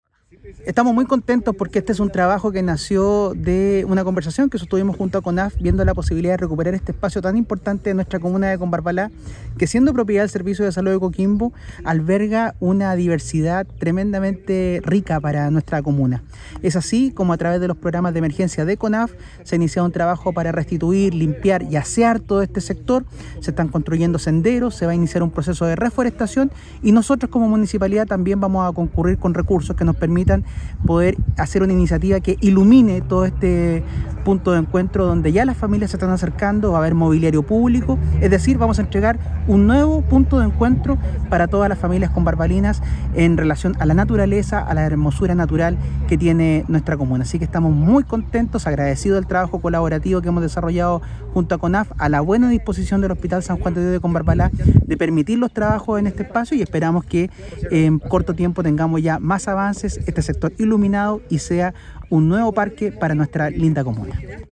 Alcalde-de-Combarbala-Pedro-Castillo-nuevo-parque-para-Combarbala.mp3